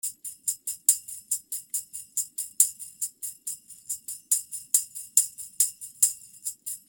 70 BPM tambourine (5 variations)
Tambourine loops in 5 variations playing at 70 bpm.